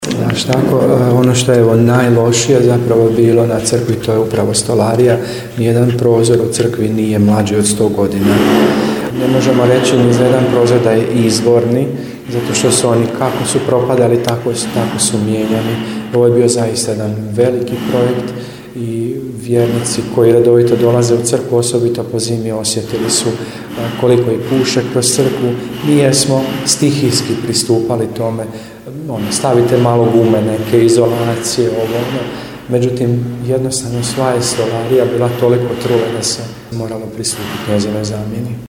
U akustičnom prostoru i bušilica nekako melodično zvuči, tako da je upravo taj radni prostor unutar crkve bio teren s kojeg prenosimo riječi, zvukove, radnu energiju…